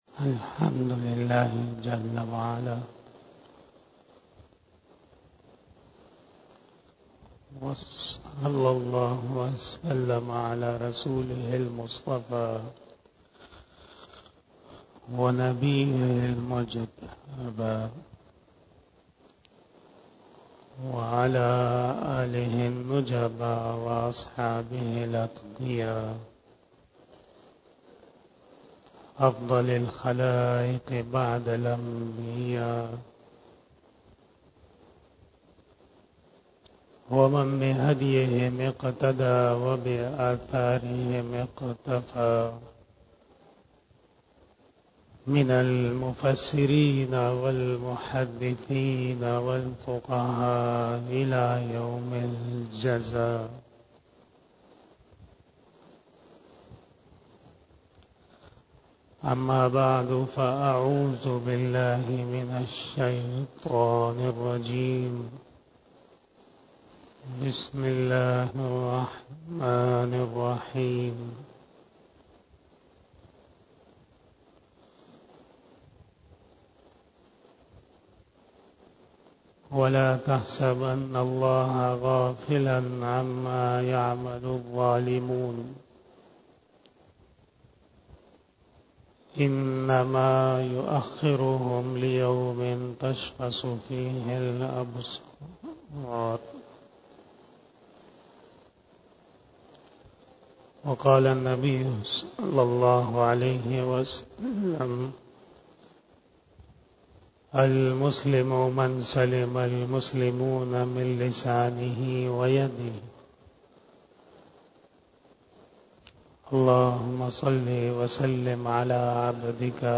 31 BAYAN E JUMMAH 04 Aug 2023 (16 Muharram ul Haraam 1444HJ)